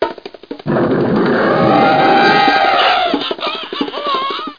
00724_Sound_jungle.mp3